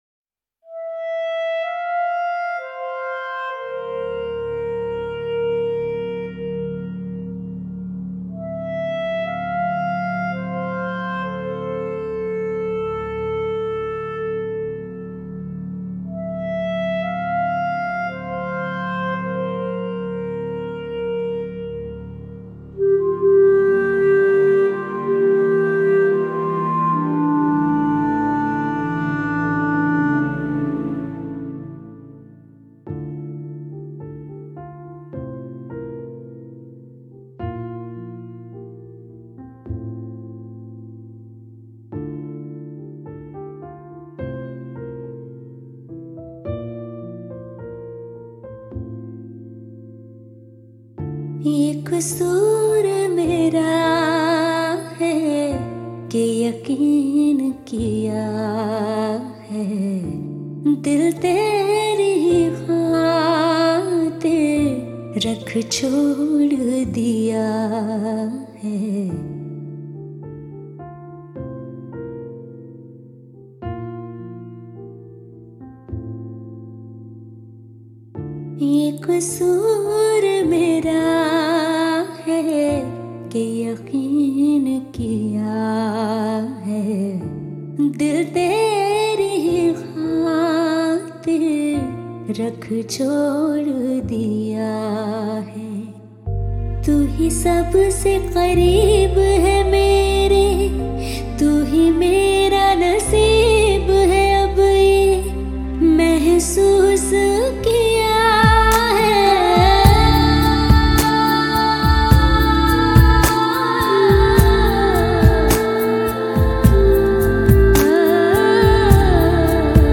Bollywood